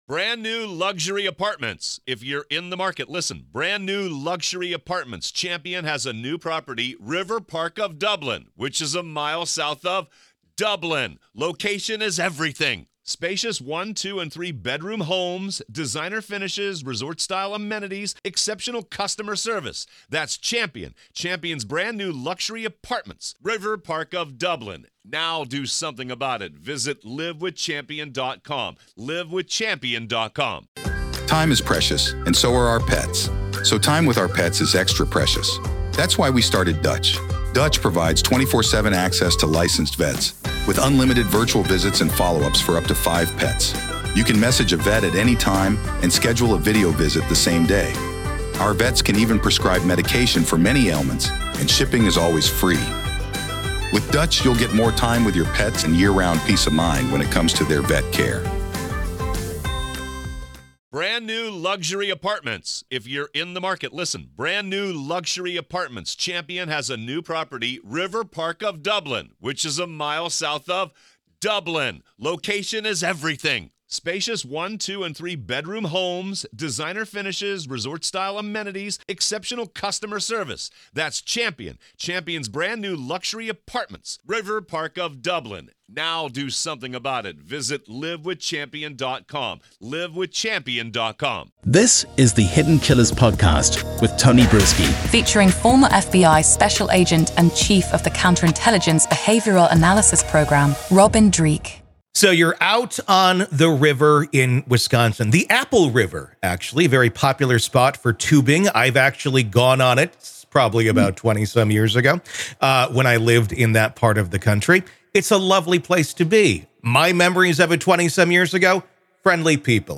This conversation uncovers the layers of human psychology, trauma responses, and the drastic consequences of split-second decisions in a moment of perceived danger.